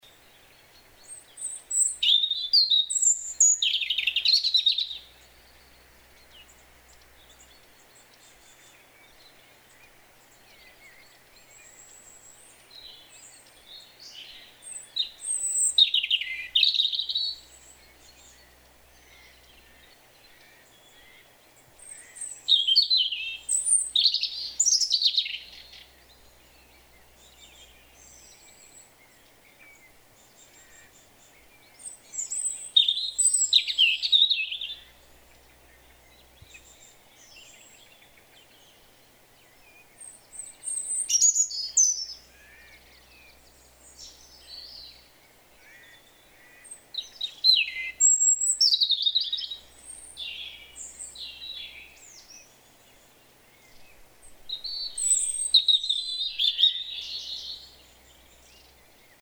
SONS DE AVES
Papinho - Erithacus rubecula